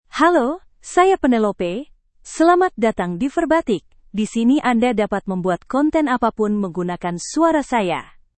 PenelopeFemale Indonesian AI voice
Penelope is a female AI voice for Indonesian (Indonesia).
Voice sample
Listen to Penelope's female Indonesian voice.
Penelope delivers clear pronunciation with authentic Indonesia Indonesian intonation, making your content sound professionally produced.